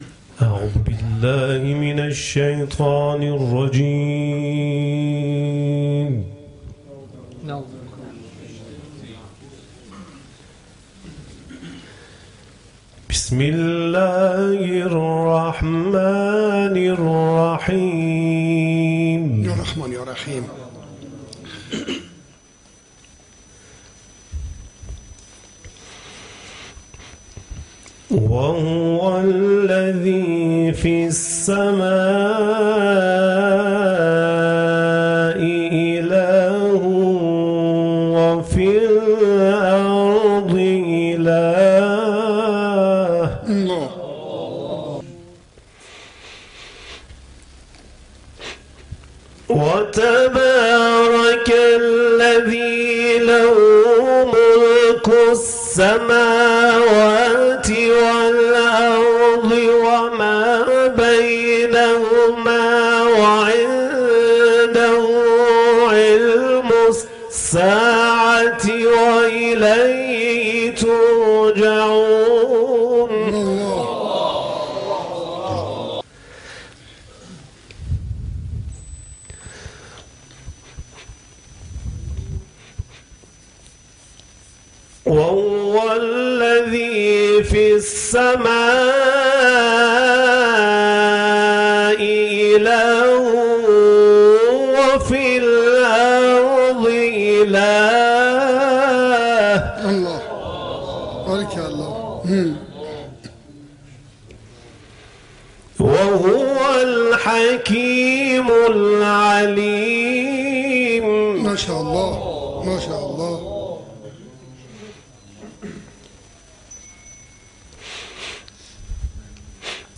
صوت تلاوت